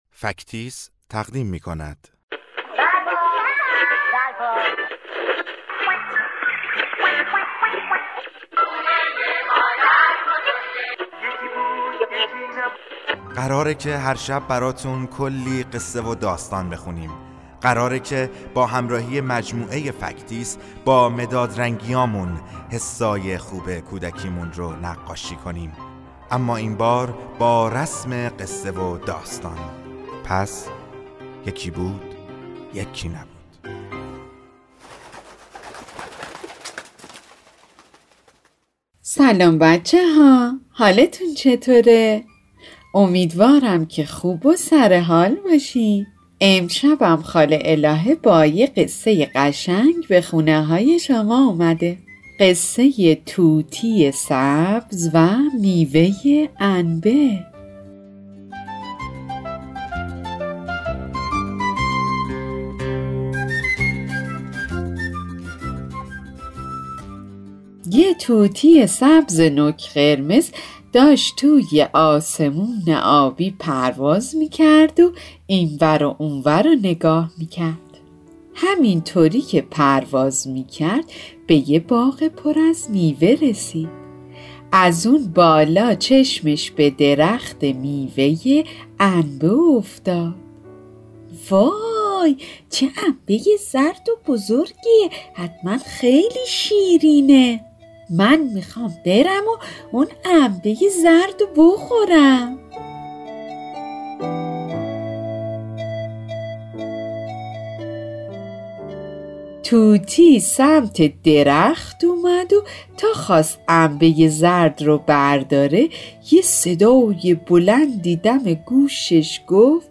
قصه کودکانه صوتی طوطی سبز و میوه انبه